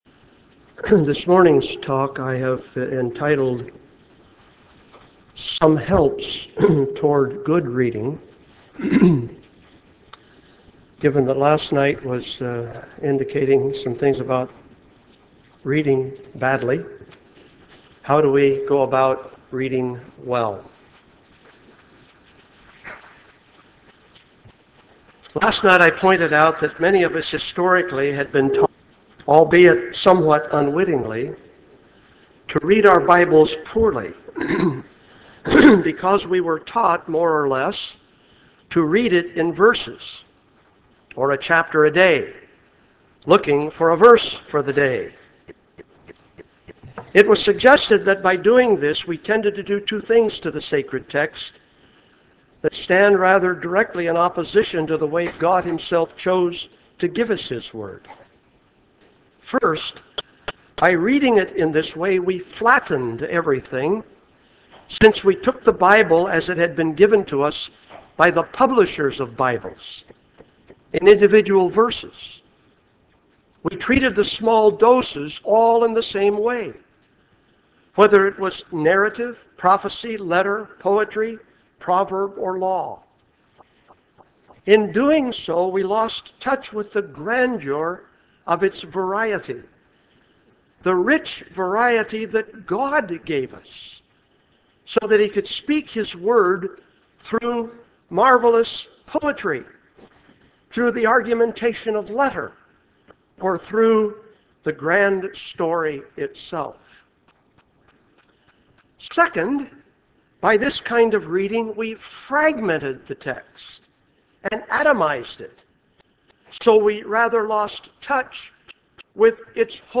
For All It's Worth Conference: Plenary 2 - Gordon Fee